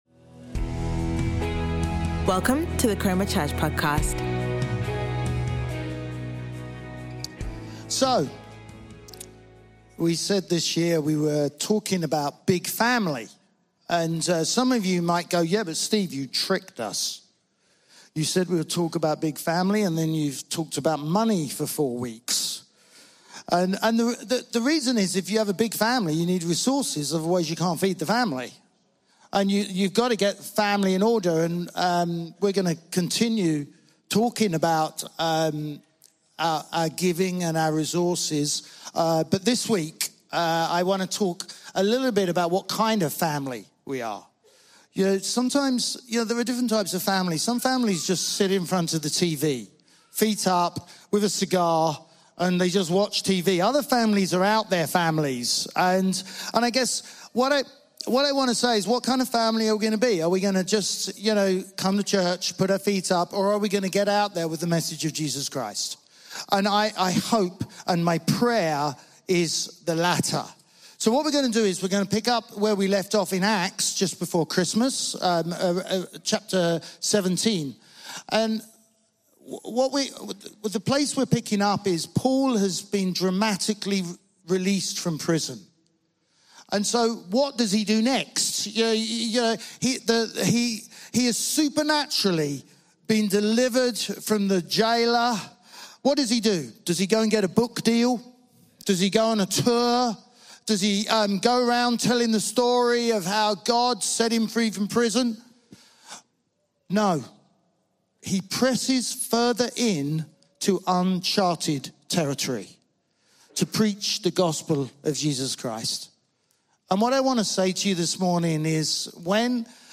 Chroma Church Live Stream
Sunday Sermon